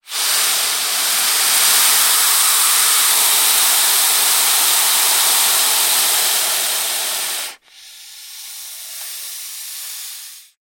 На этой странице собраны реалистичные аудиозаписи: от угрожающего шипения до плавного скольжения по поверхности.
Звук Питон - Другой вариант